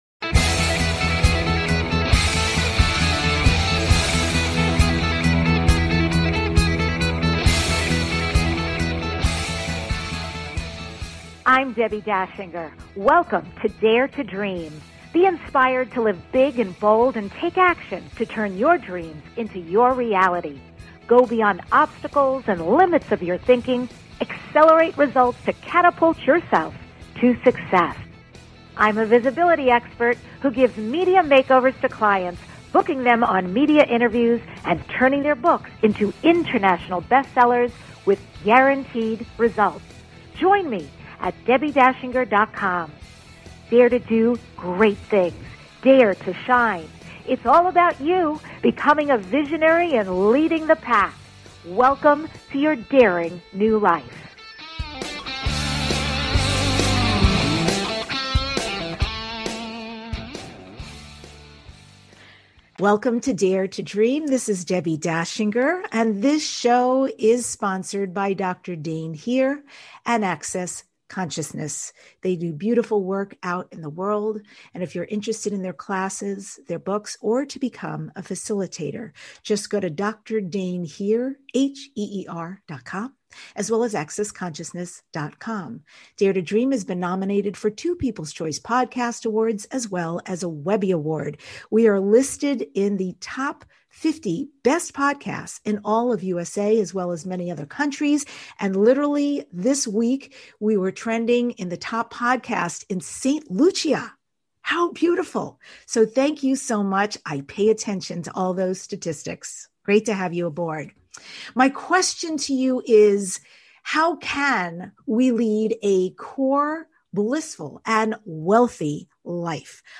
The award-winning DARE TO DREAM Podcast is your #1 transformation conversation.